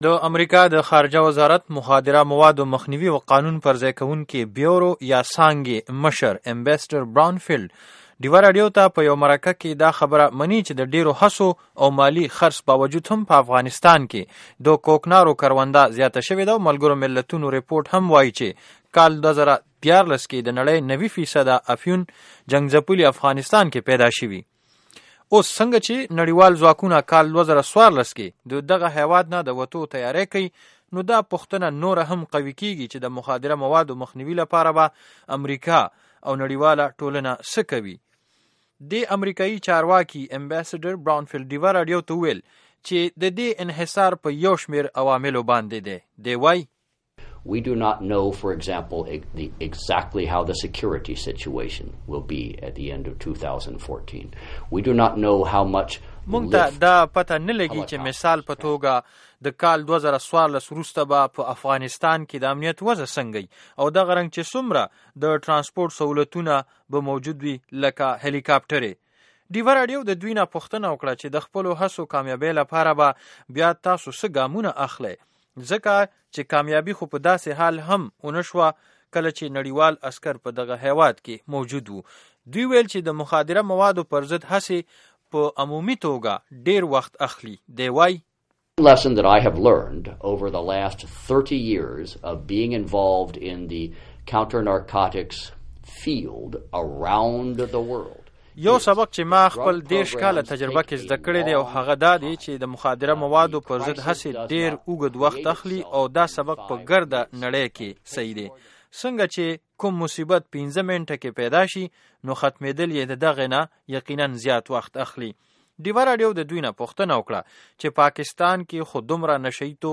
د امریکا د نشۍ توکو د مخنیوي ادارې مشر ایمبیسډر براؤن فیلډ مرکه